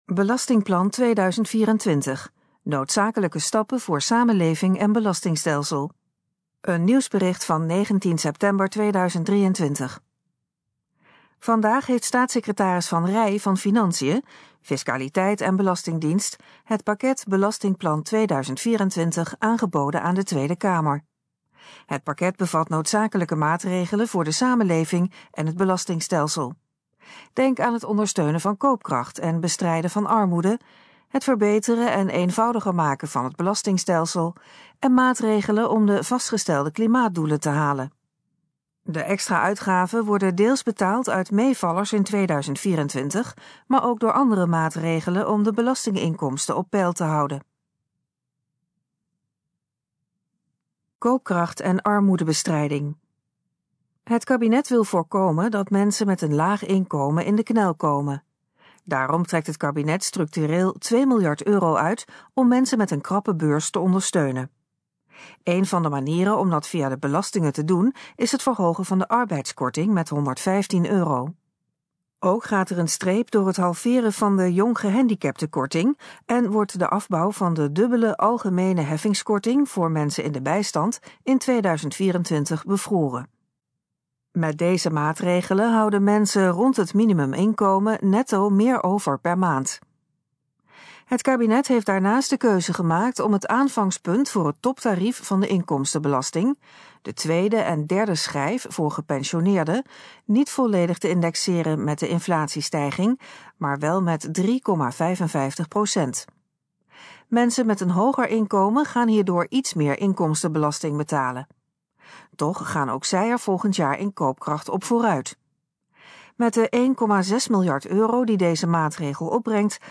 Gesproken versie van Belastingplan 2024: noodzakelijke stappen voor samenleving en belastingstelsel
In het volgende geluidsfragment hoort u informatie over het Belastingplan 2024. Het fragment is de gesproken versie van de informatie op de pagina Belastingplan 2024: noodzakelijke stappen voor samenleving en belastingstelsel.